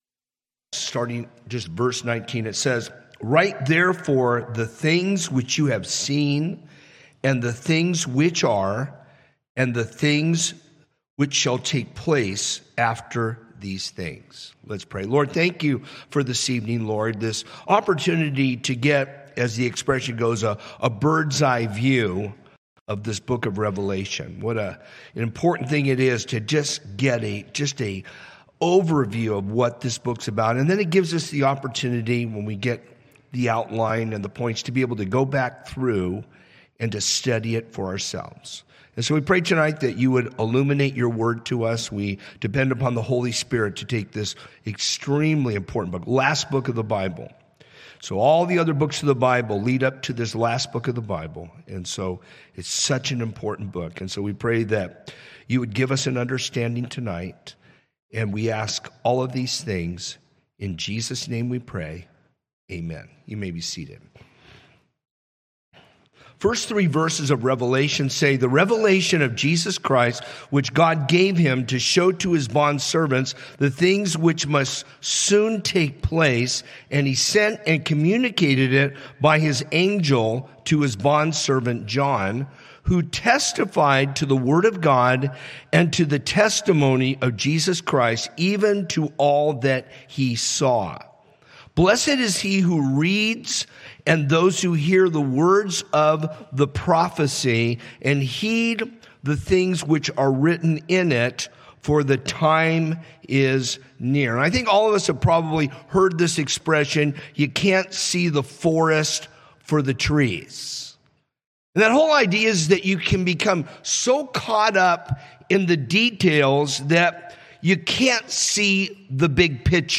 A message from the series "Guest Speaker." - Truthful self-assessment. - Respond to the Spirit’s leading.